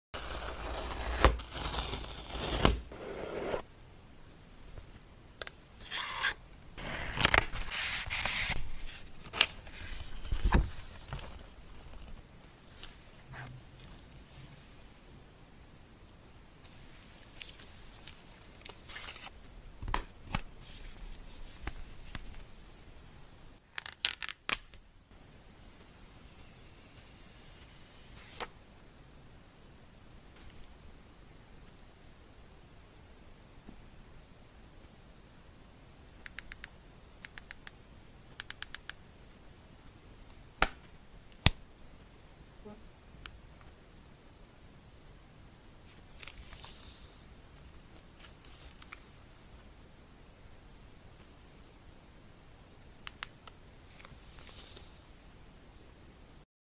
Samsung Fold 7 Unboxing ASMR sound effects free download